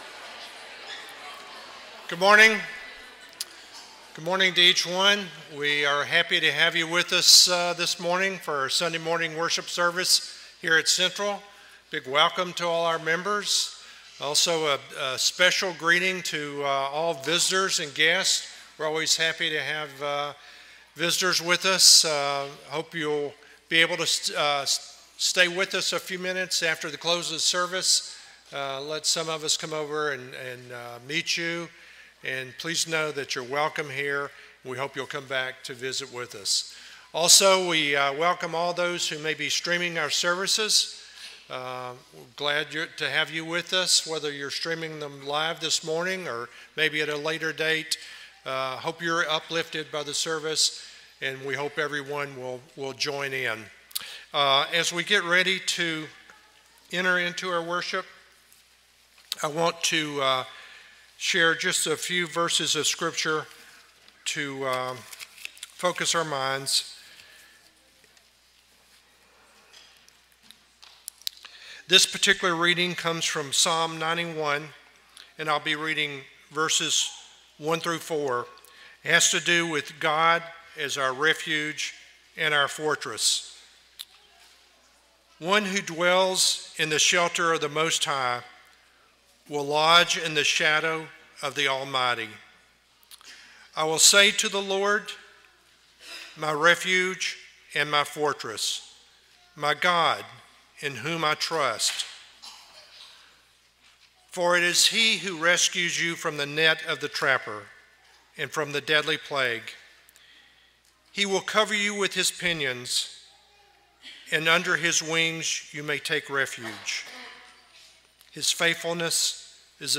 Psalm 145:18, English Standard Version Series: Sunday AM Service